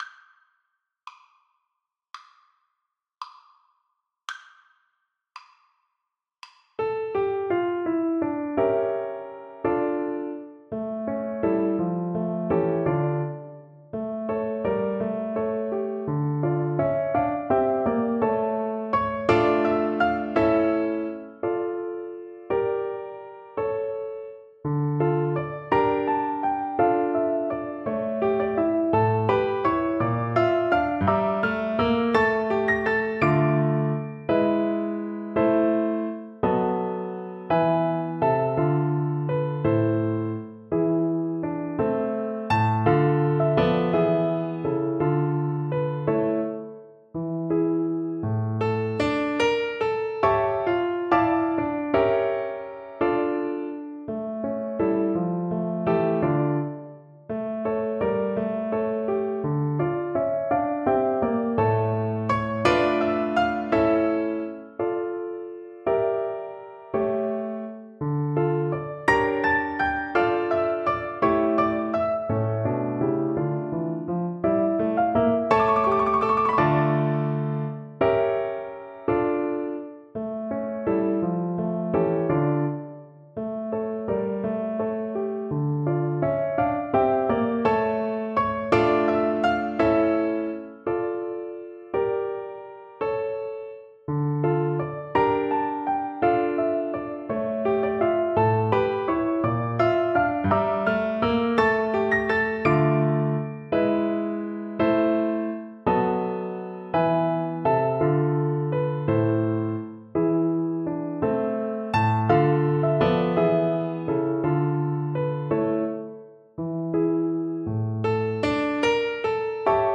Allegretto con moto .=56
12/8 (View more 12/8 Music)
A5-D7
Classical (View more Classical Flute Music)